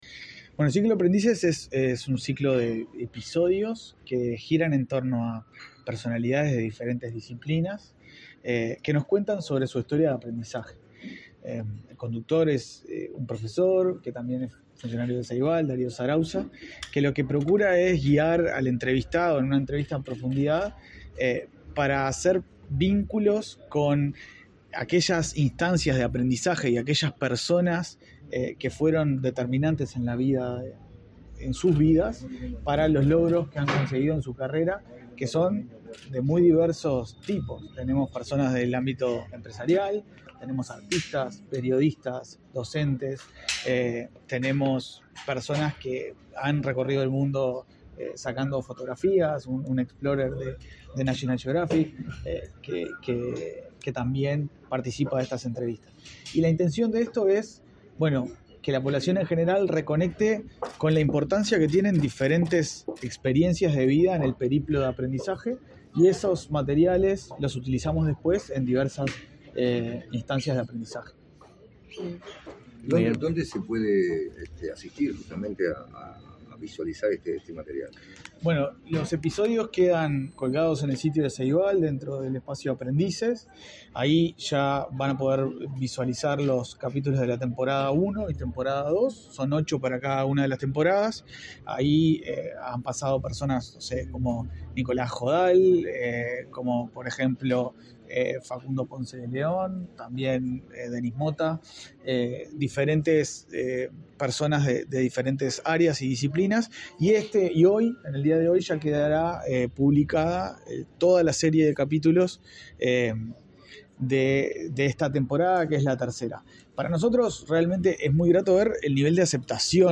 Declaraciones del ‌presidente de Ceibal, Leandro Folgar
En diálogo con la prensa, el presidente del Ceibal, Leandro Folgar, destacó la importancia de esta herramienta.